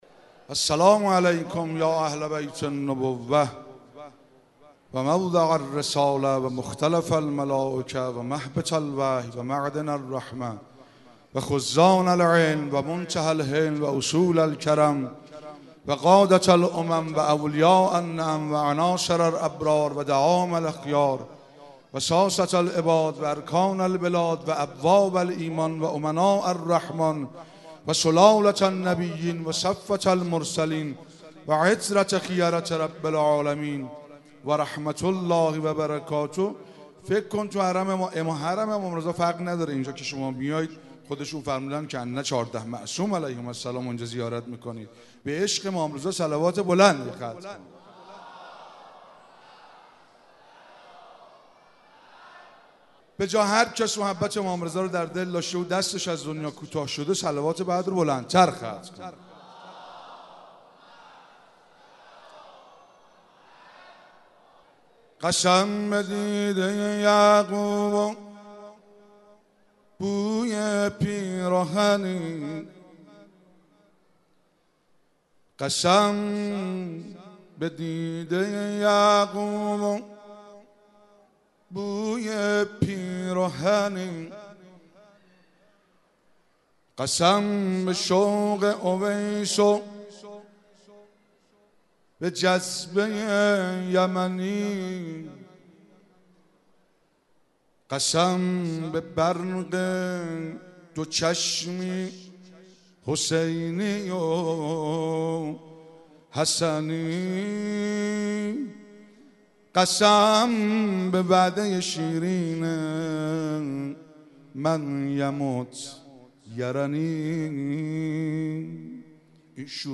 تکیه | مدح امام رضا علیه السلام
شام میلاد پر برکت حضرت رضا علیه السلام